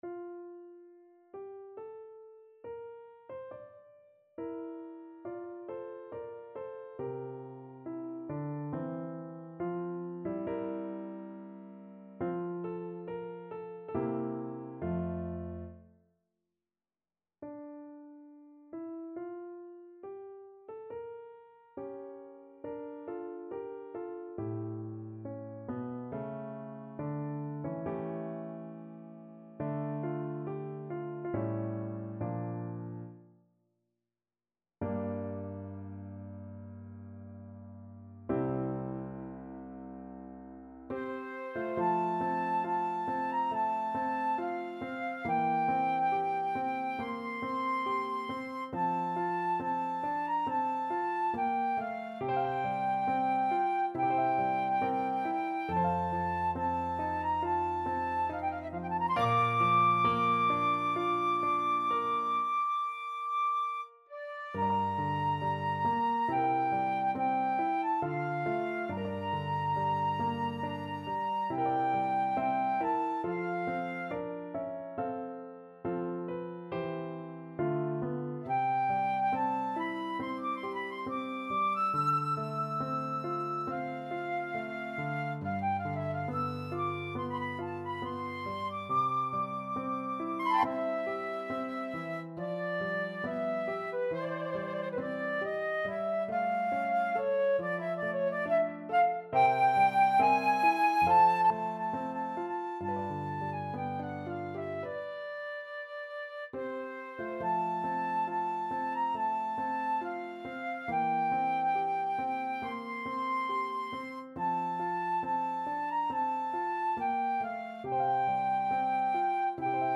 4/4 (View more 4/4 Music)
Larghetto (=80) =69